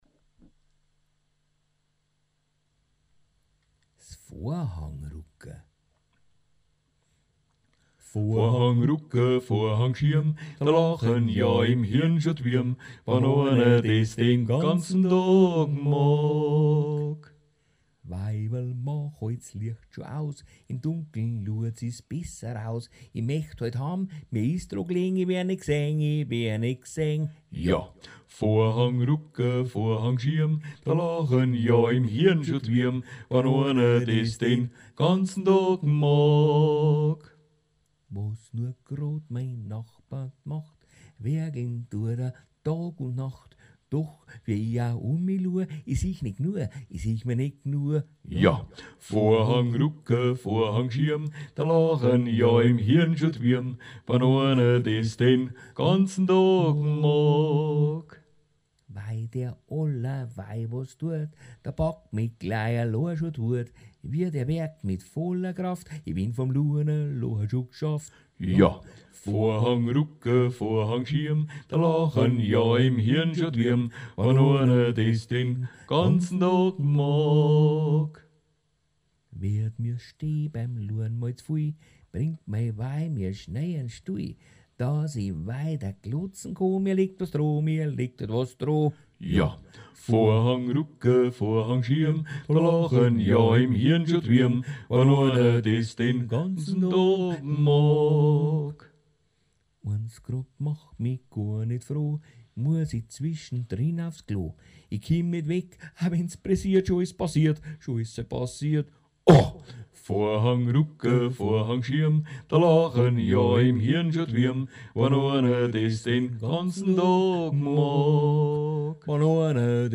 Duett (mit mir selbst) im April 2011 aufgenommen